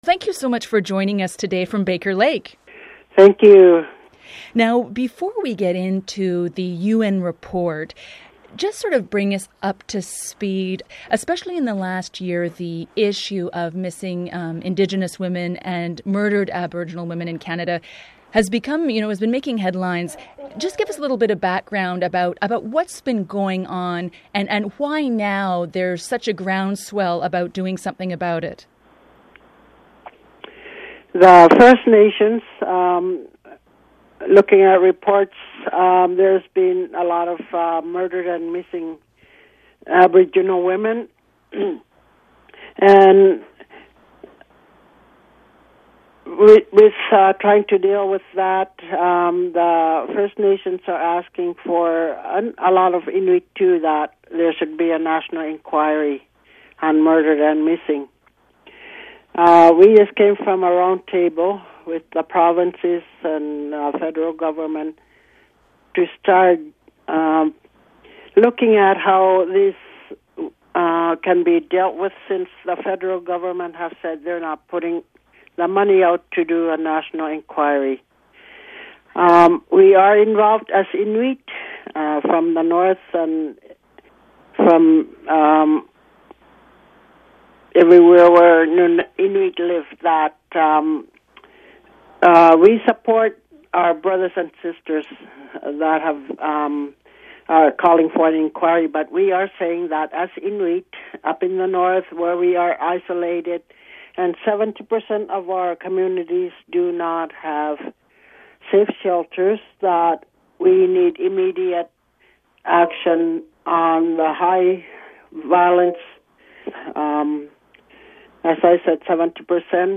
Feature Interview: Fighting domestic violence in remote northern communities